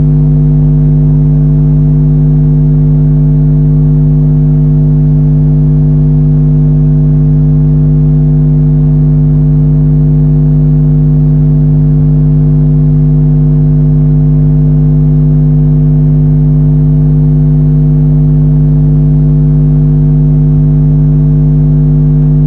Â In this case, this time, the microphone is located close behind the counter.
EM induction recording of speak thinking
The recording: reduce the volume to hear the modulations if it is not clear. Â Remember that you are listening to the hum that is modulating to “Jack and Jill…”.
Â Remember here during this experiment I was virtually silent but for my thinking and hence “speak thinking”.